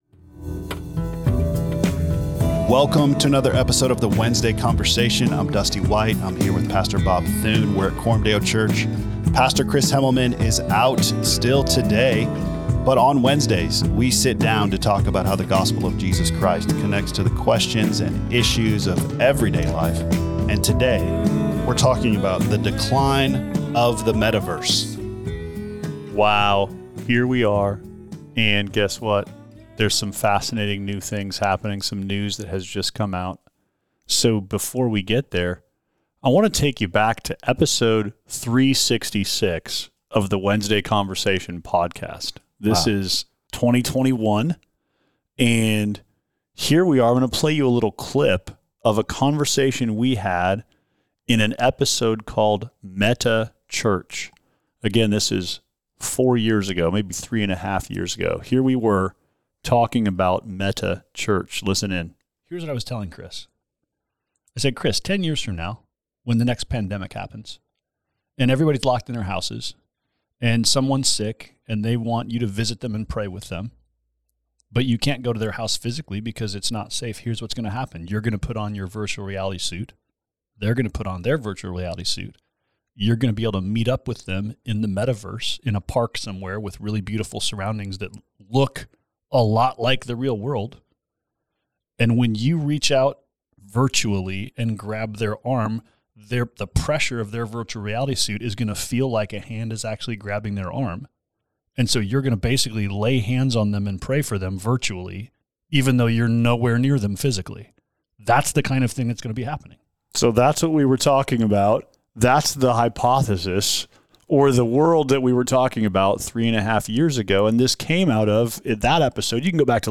A weekly conversation about how the gospel of Jesus Christ connects to the questions and issues of everyday life. Hosted by the leaders of Coram Deo Church in Omaha, Nebraska.